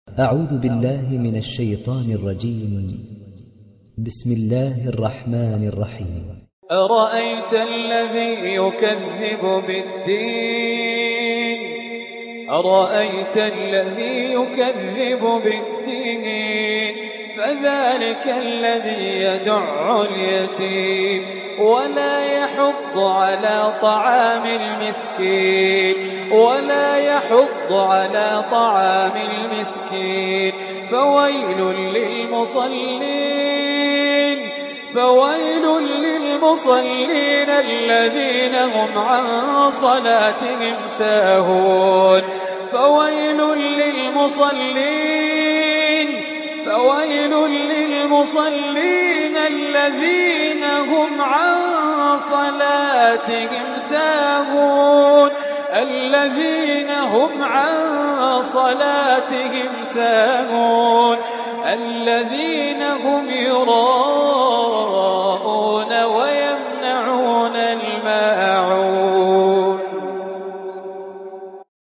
تحميل سورة الماعون mp3 بصوت خالد الجليل برواية حفص عن عاصم, تحميل استماع القرآن الكريم على الجوال mp3 كاملا بروابط مباشرة وسريعة